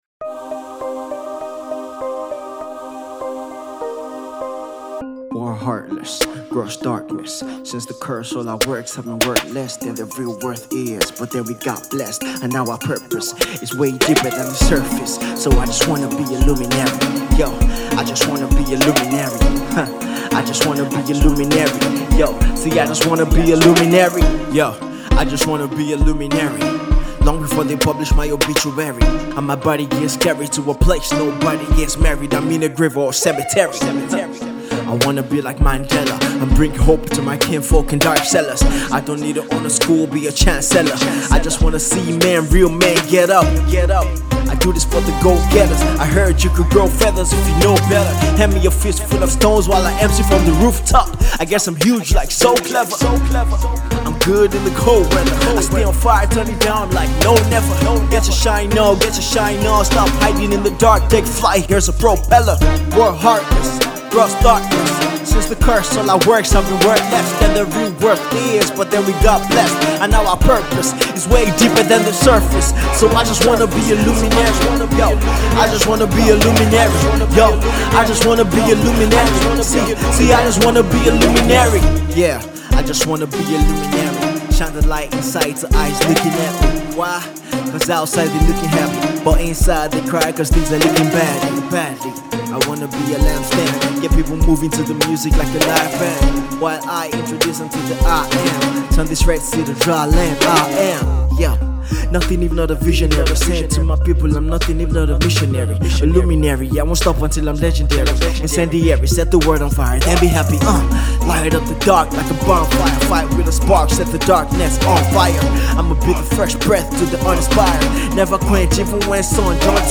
hip-hop artiste and producer.